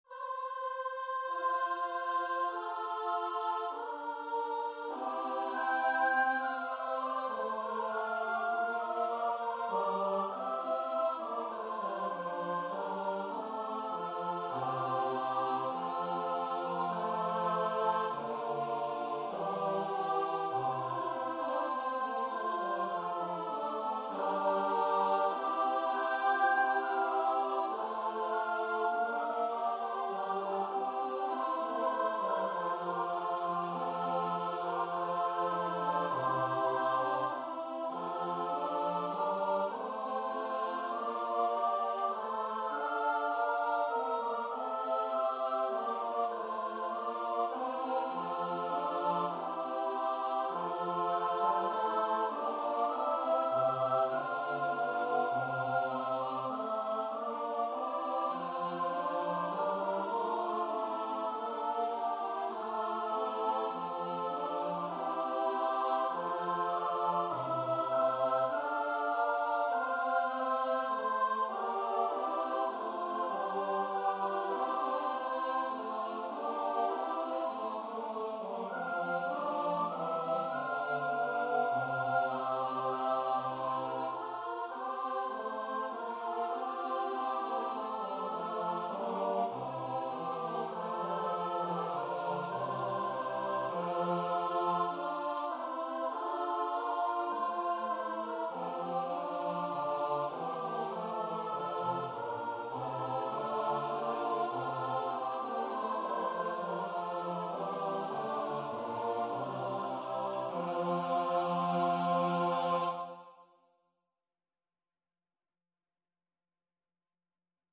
Free Sheet music for Choir
F major (Sounding Pitch) (View more F major Music for Choir )
2/2 (View more 2/2 Music)
Choir  (View more Intermediate Choir Music)
Classical (View more Classical Choir Music)